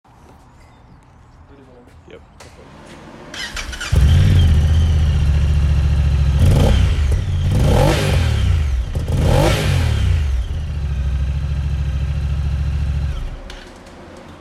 This Honda Talon Slip On Exhaust By Force Turbos Is Easy To Install With A Deep Low Tune . Slightly Louder Than Stock.
• Deep exhaust tone
• Decibels: 105.5 (5dB louder vs. stock)
Honda_Talon_Force_Exhaust.mp3